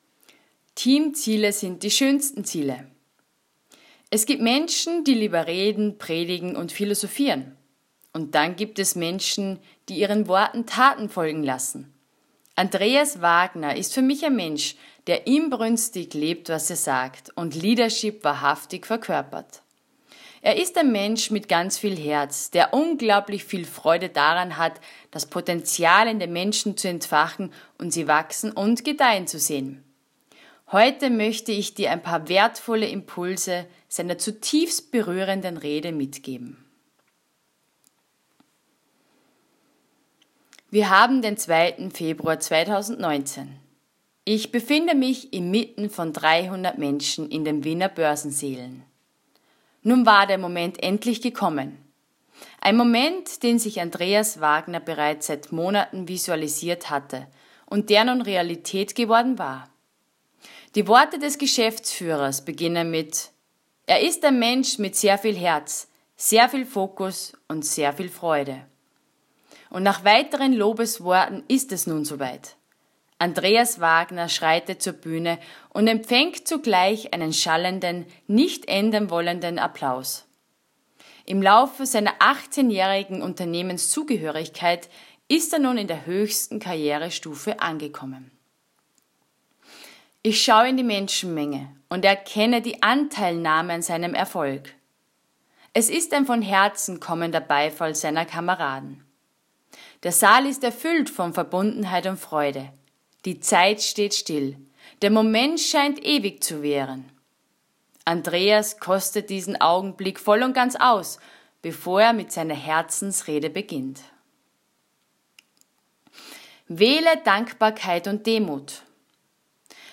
Ich befinde mich inmitten von 300 Menschen in den Wiener Börsensälen.